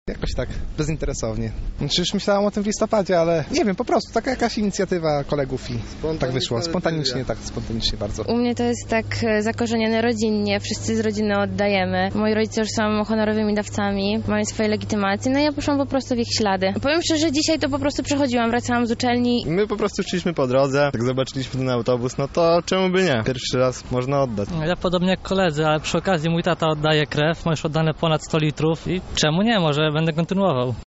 Zapytaliśmy lublinian dlaczego zdecydowali się na to.